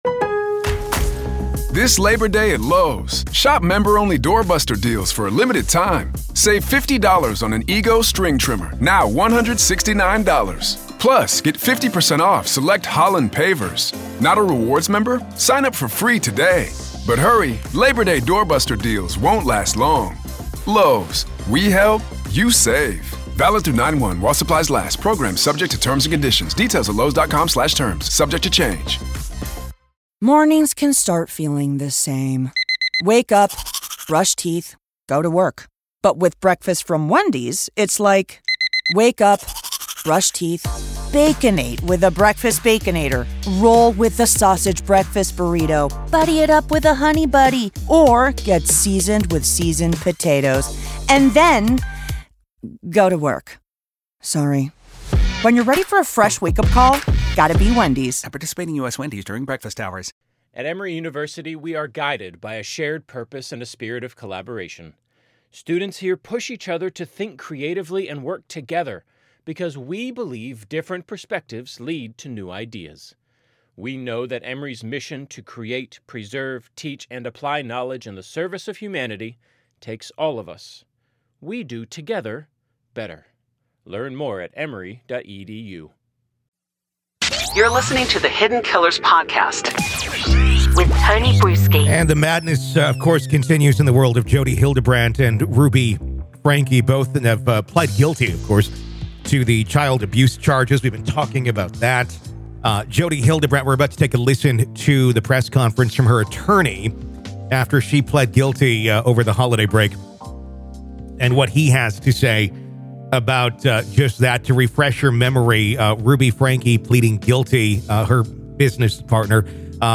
addressed the press outside a courthouse in Utah.